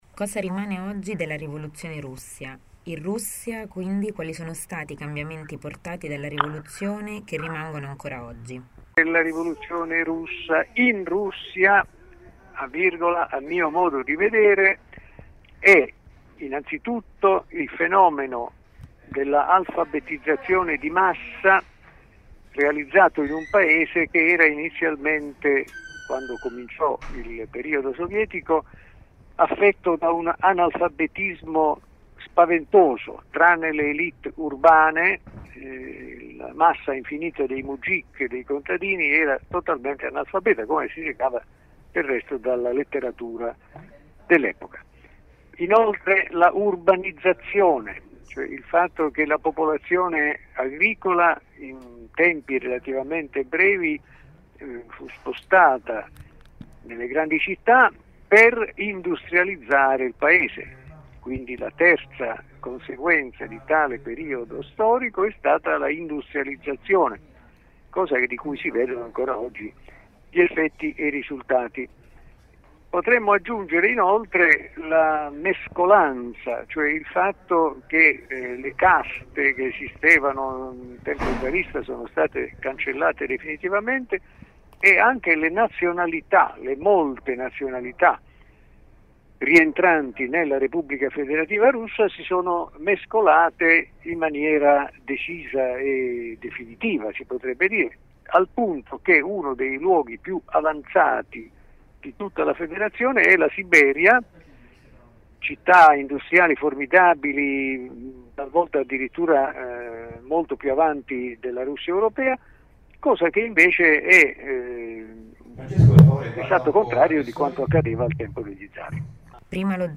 INTERVISTA-A-LUCIANO-CANFORA.mp3